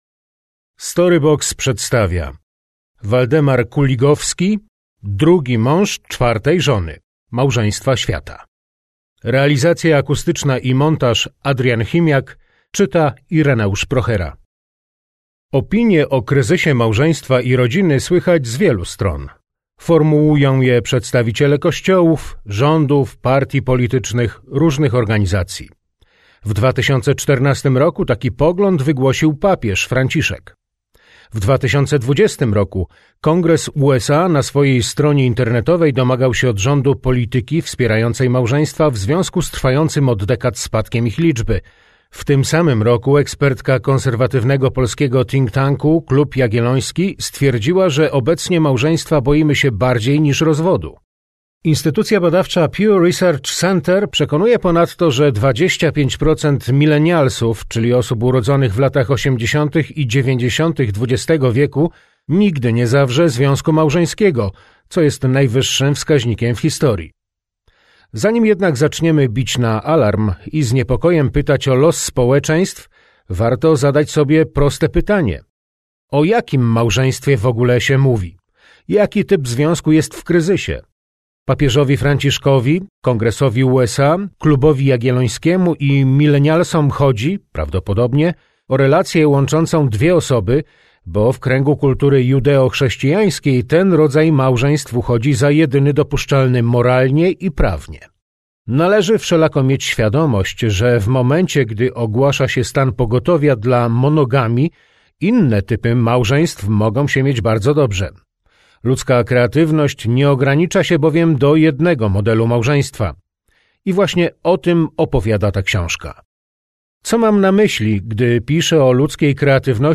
Drugi mąż czwartej żony - Kuligowski Waldemar - audiobook